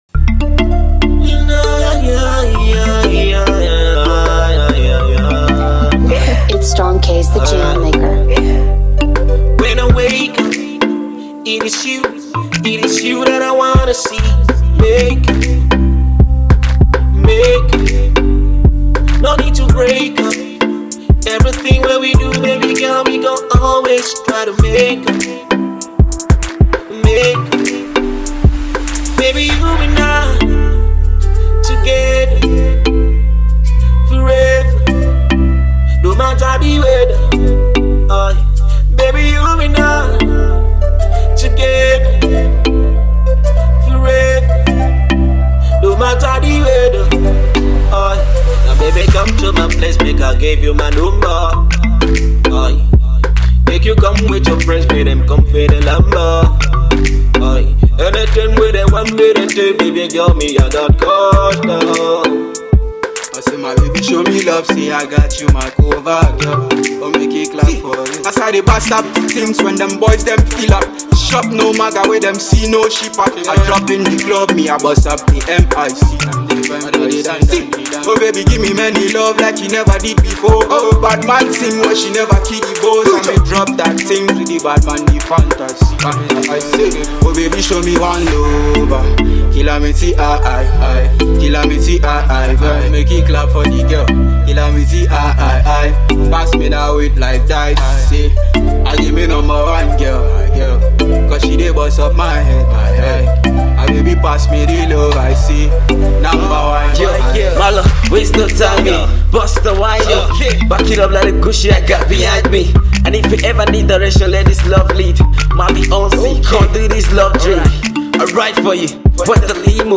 Genre of Song – reggae